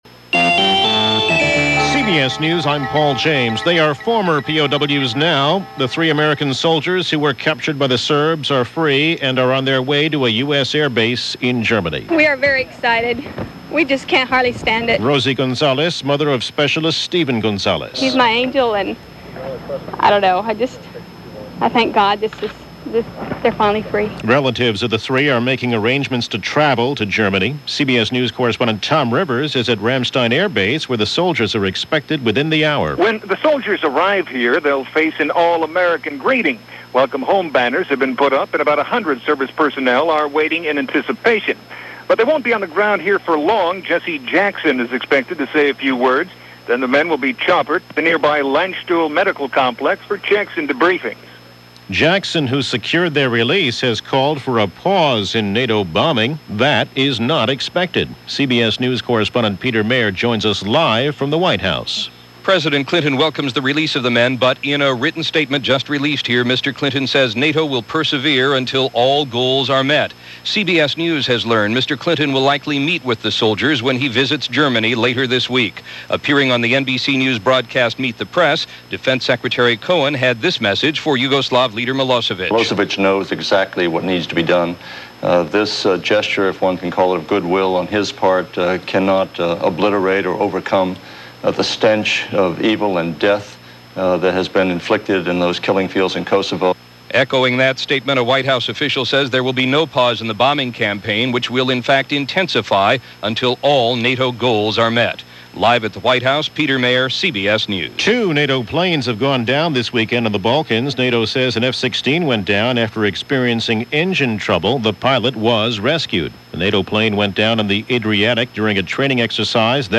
And that’s a small slice of what went on, this May 2, 1999 as presented by CBS Radio News.